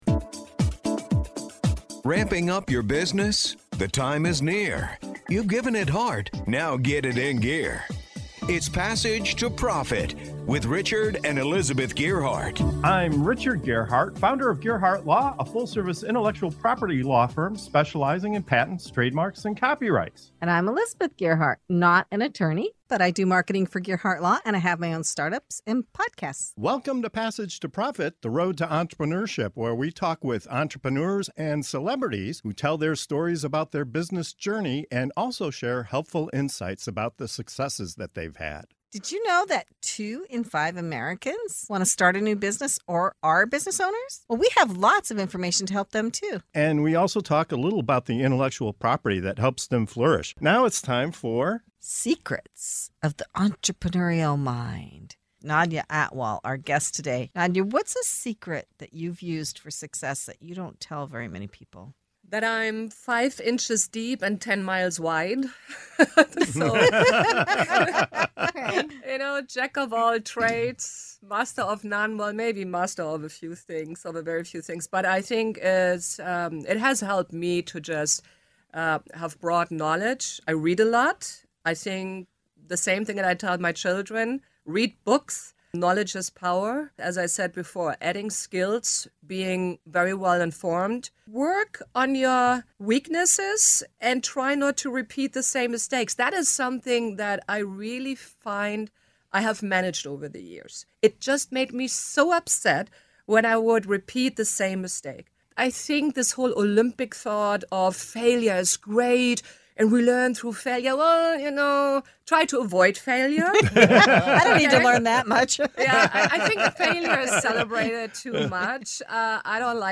From the art of mastering many skills to learning from success (not just failure!), our guests share their hard-won wisdom on growth, resilience, and staying adaptable in business. Plus, a dose of Warren Buffett’s advice and a fresh perspective on embracing new ideas.